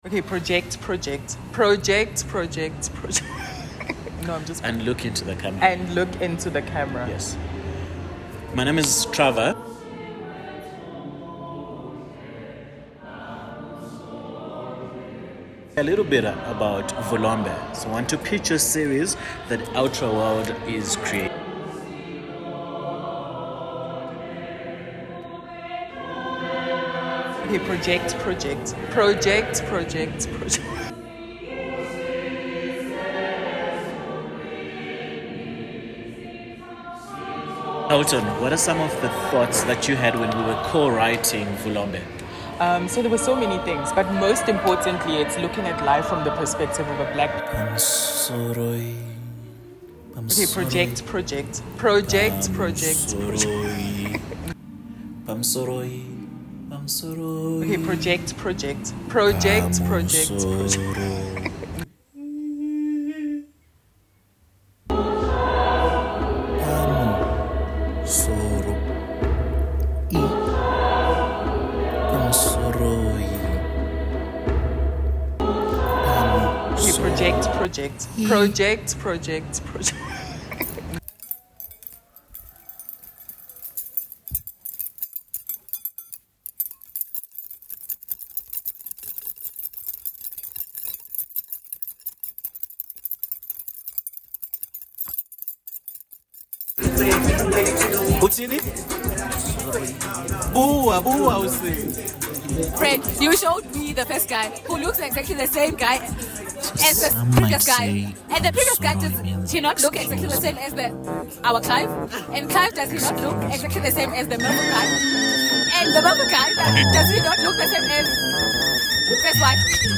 Sonic Kumema Poetic Uhambo: An experimental sonic and poetry readings in short chants as an autoethnographic embodied wanderings translating part of the authors reflections while living in the diaspora
The sonic recordings were created between 2023 and 2024.